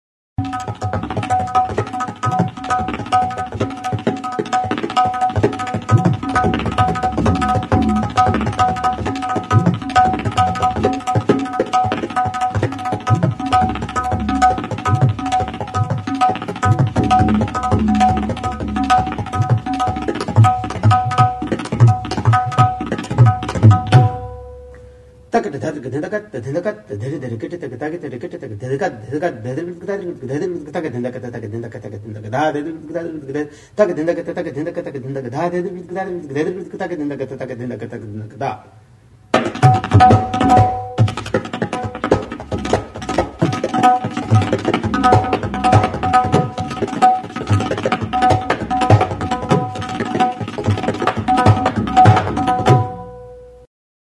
Instruments de musiqueTABLA
Membranophones -> Frappés -> Frappés à l'aide des mains
ASIE -> INDIA